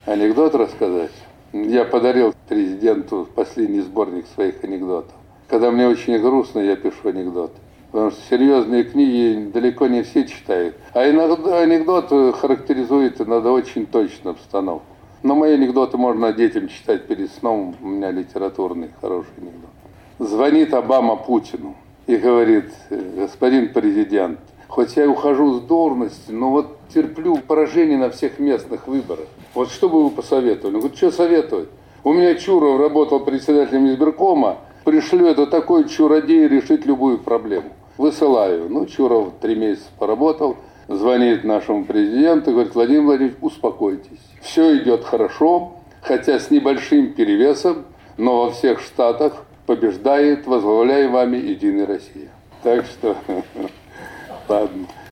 Камуніст Зюганаў расказвае анэкдот пра дэмакрата Абаму